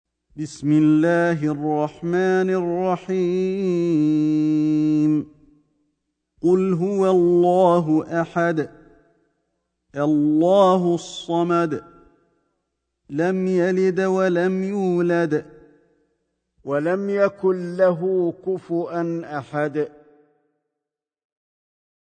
سورة الإخلاص > مصحف الشيخ علي الحذيفي ( رواية شعبة عن عاصم ) > المصحف - تلاوات الحرمين